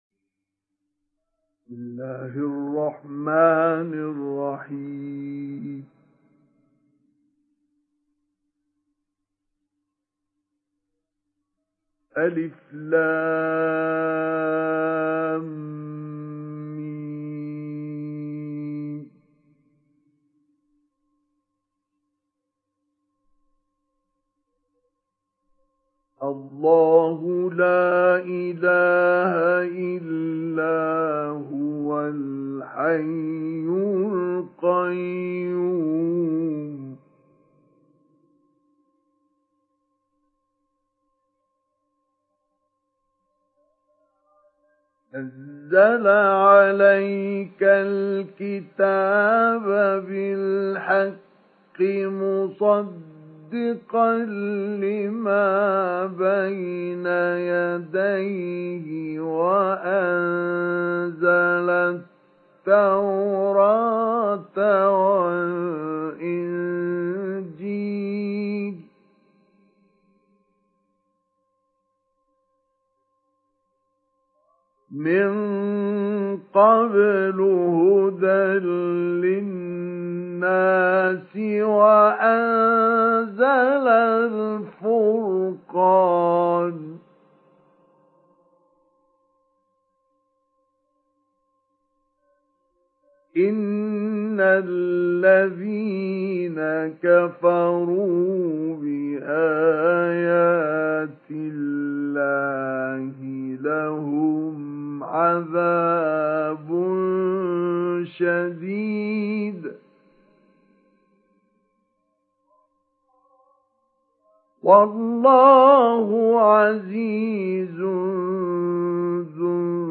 تحميل سورة آل عمران mp3 بصوت مصطفى إسماعيل مجود برواية حفص عن عاصم, تحميل استماع القرآن الكريم على الجوال mp3 كاملا بروابط مباشرة وسريعة
تحميل سورة آل عمران مصطفى إسماعيل مجود